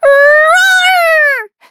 Taily-Vox_Attack6.wav